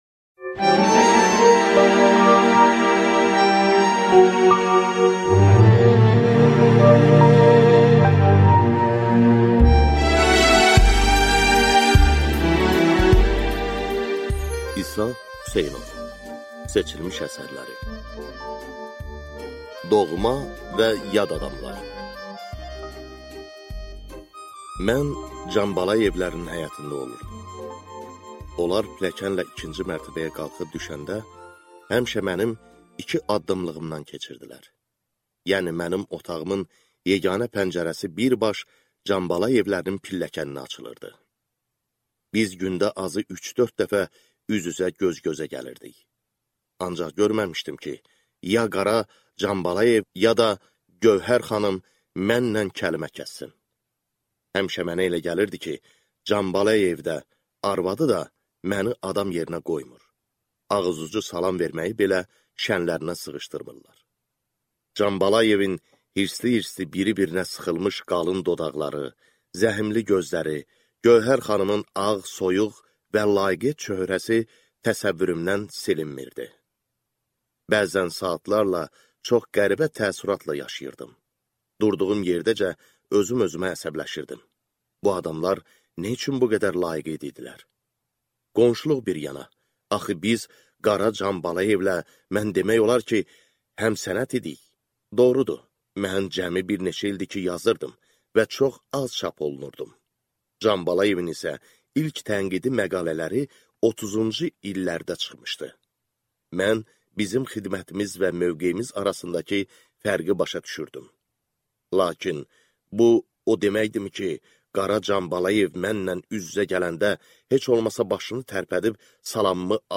Aудиокнига Seçilmiş əsərlər Автор İsa Hüseynov